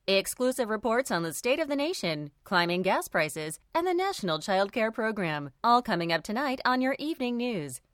Female
Television Spots
News Report